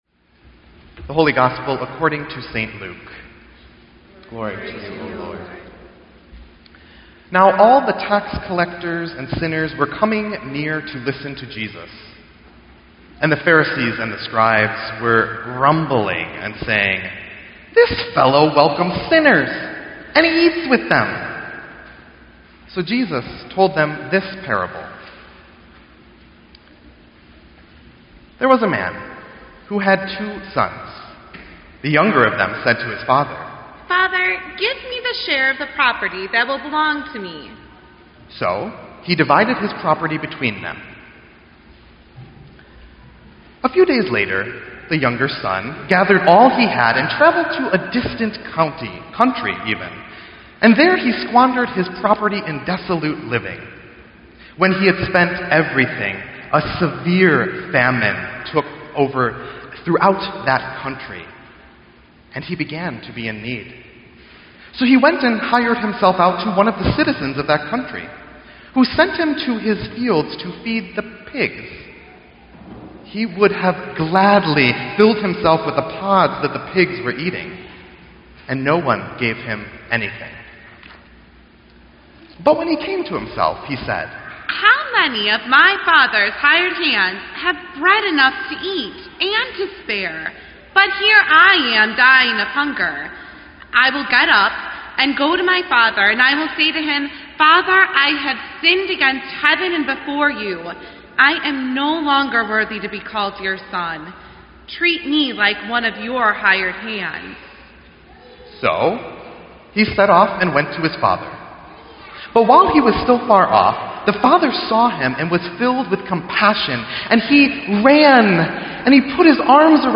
Sermon_3_6_16.mp3